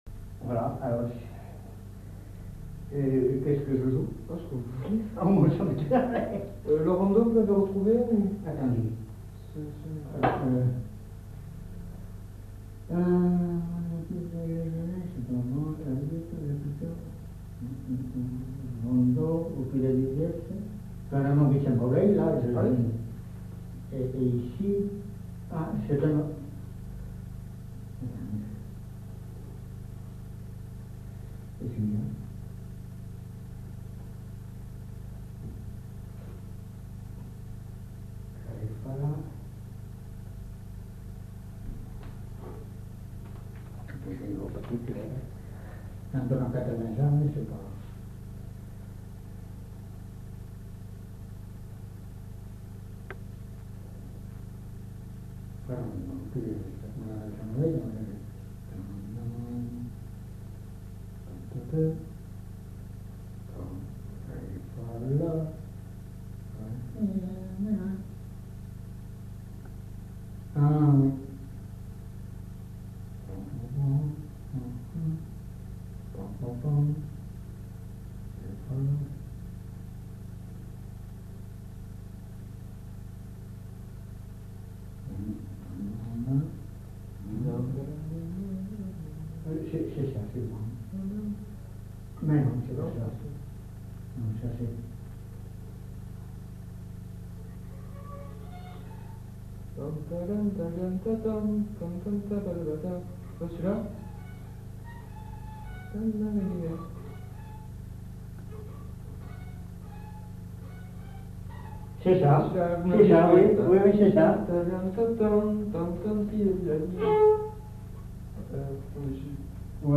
Aire culturelle : Bazadais
Lieu : Bazas
Genre : morceau instrumental
Instrument de musique : violon
Danse : rondeau